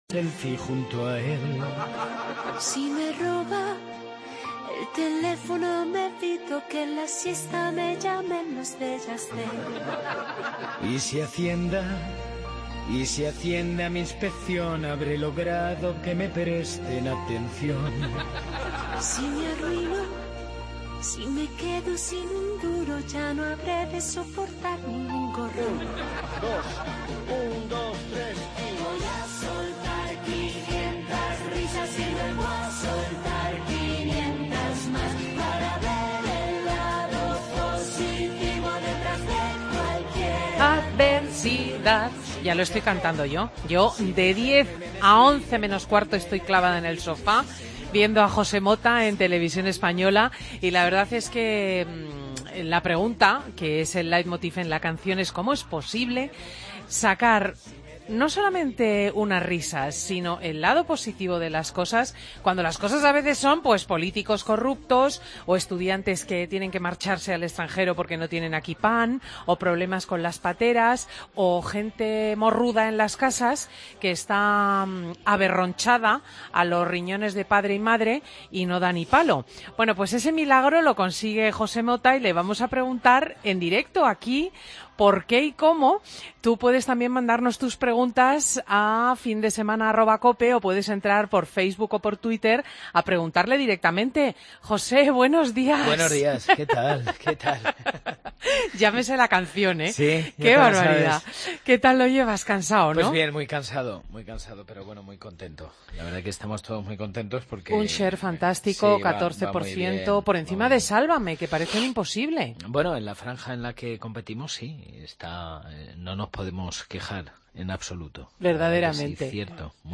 Entrevista a José Mota en Fin de Semana